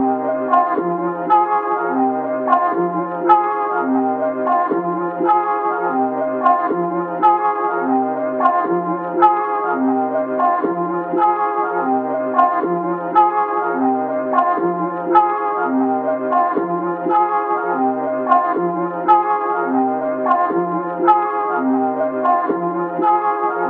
标签： 81 bpm Trap Loops Drum Loops 3.99 MB wav Key : Unknown
声道立体声